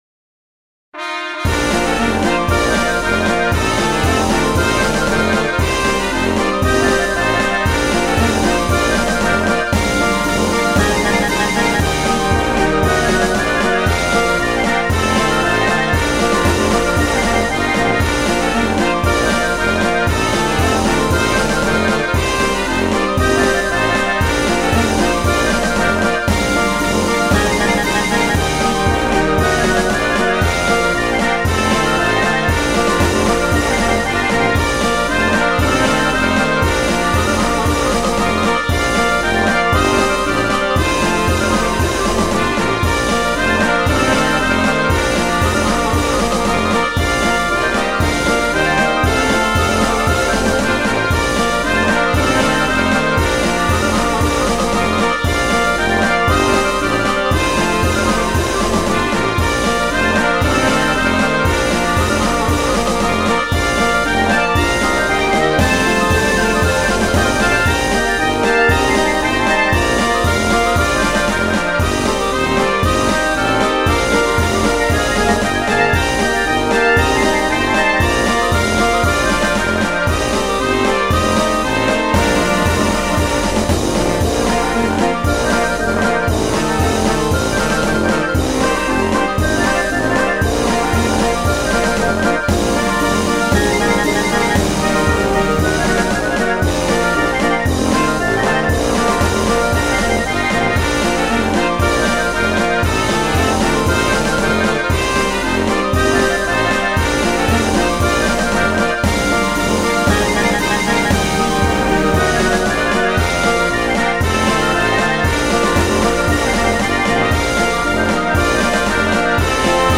BGM
ロング明るい激しい